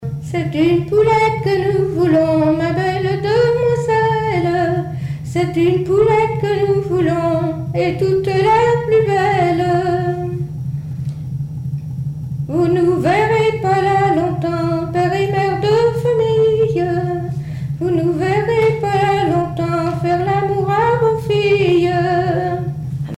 Mémoires et Patrimoines vivants - RaddO est une base de données d'archives iconographiques et sonores.
Chants brefs - Conscription
gestuel : à marcher
Témoignages et chansons
Pièce musicale inédite